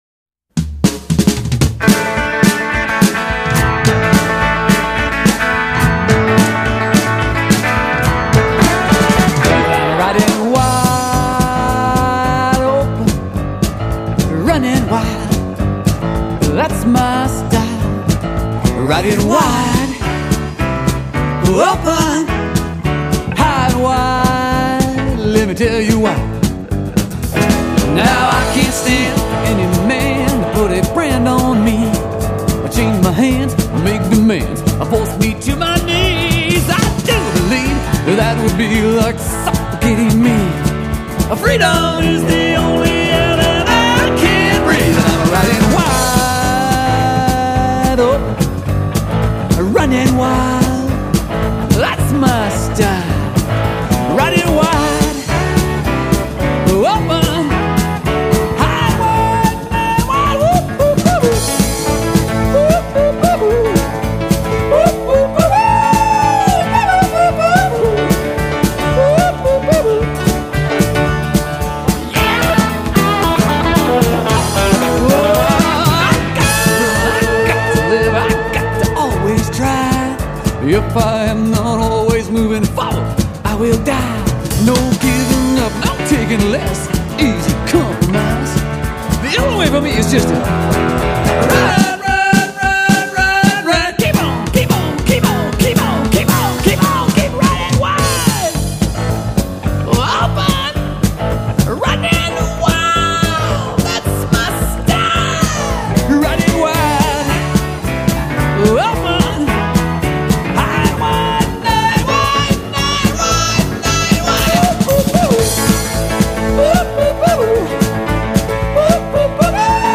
vocals, guitar
Bass
Drums
Telecaster, grand piano